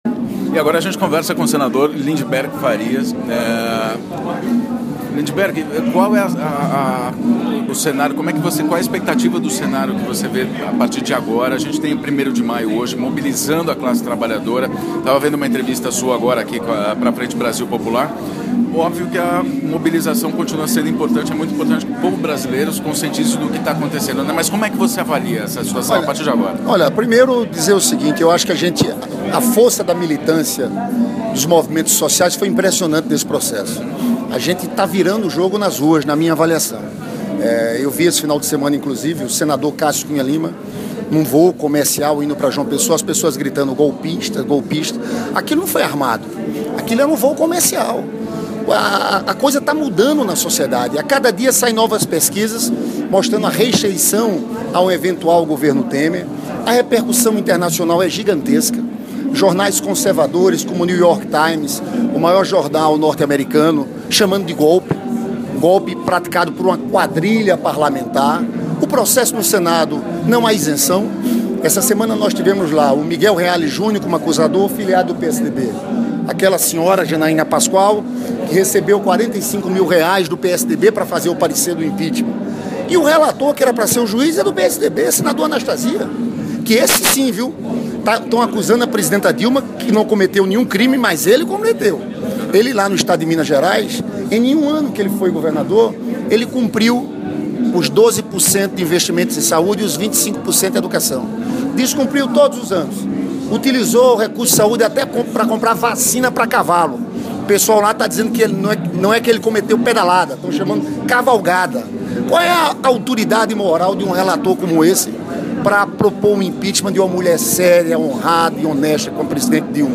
Entrevista com o Senador Lindberg Farias ao vivo do 1° de Maio
entrevista-com-o-senador-lindberg-farias-ao-vivo-do-1-de-maio-0d34